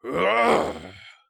ZS嘲弄1.wav
ZS嘲弄1.wav 0:00.00 0:01.30 ZS嘲弄1.wav WAV · 112 KB · 單聲道 (1ch) 下载文件 本站所有音效均采用 CC0 授权 ，可免费用于商业与个人项目，无需署名。
人声采集素材/男3战士型/ZS嘲弄1.wav